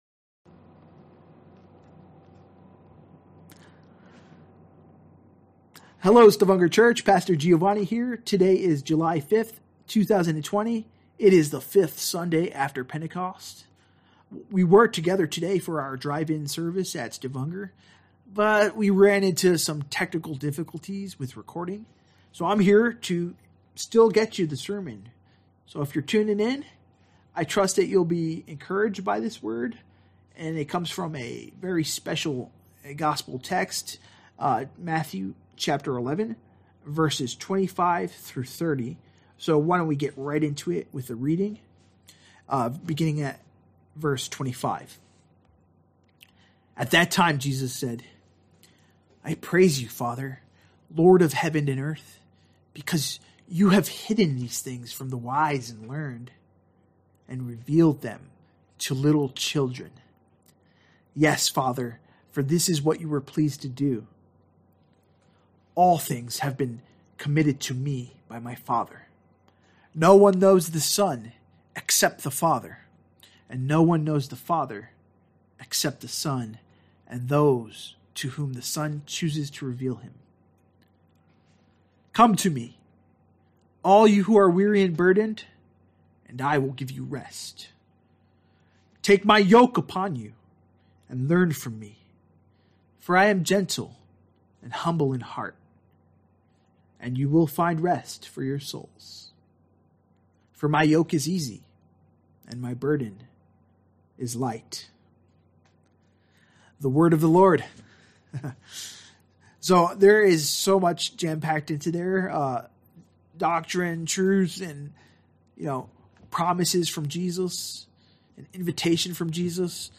Sunday Worship ( Sermon Only) - Weary & Burdened - Matthew 11: 25-30